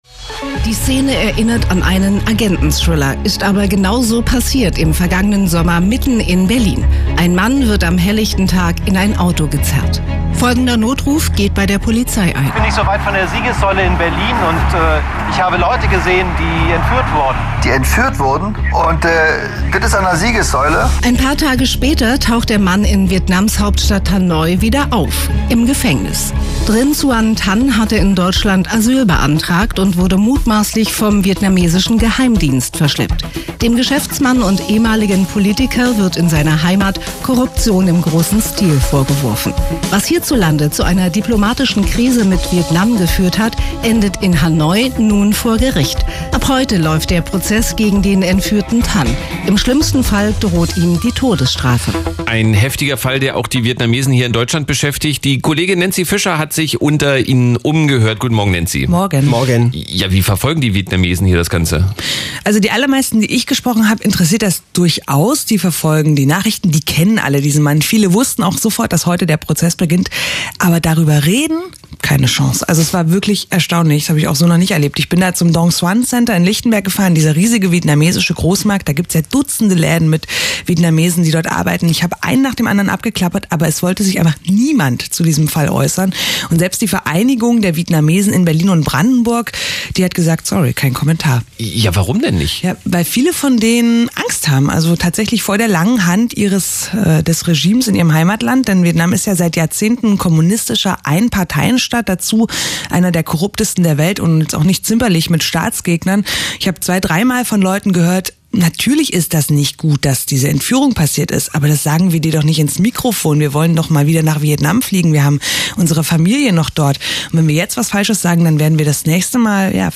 Heute beginnt in Hanoi der Prozess gegen den in Berlin entführten Trinh Xuan Thanh. Was denkt die vietnamesische Community in Berlin darüber?